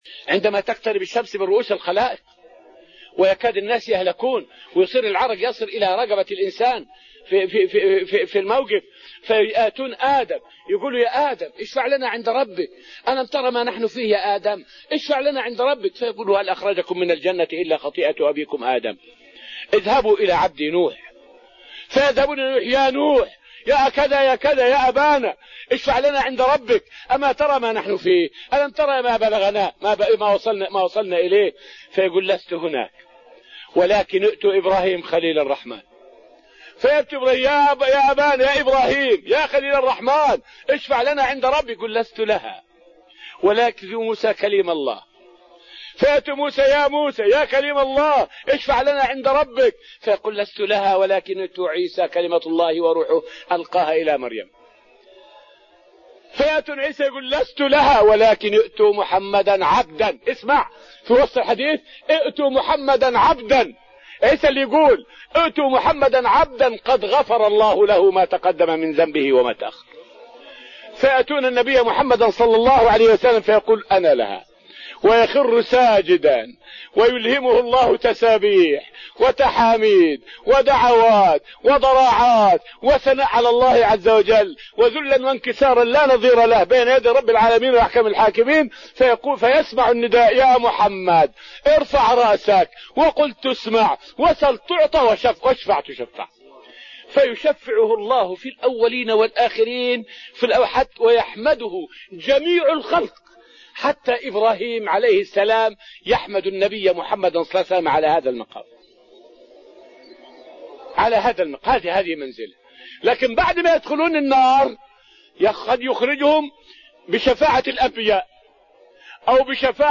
فائدة من الدرس السابع من دروس تفسير سورة الأنفال والتي ألقيت في رحاب المسجد النبوي حول الشفاعة.